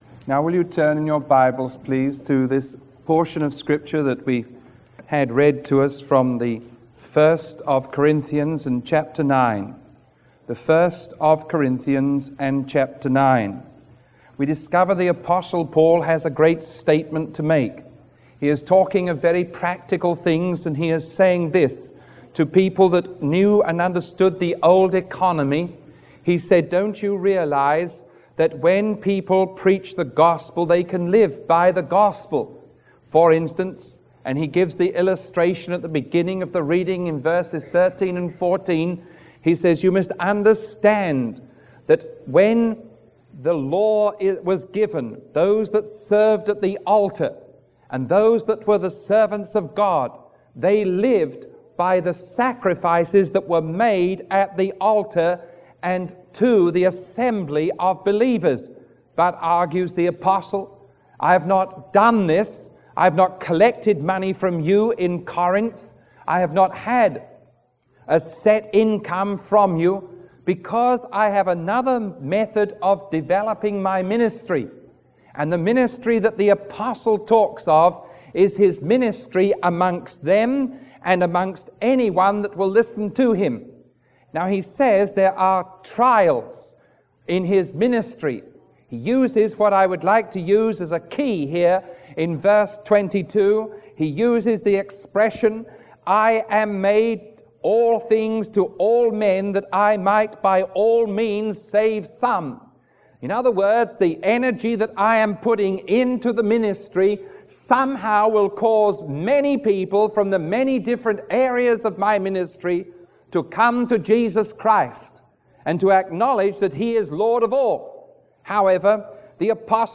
Sermon 0411A recorded on September 6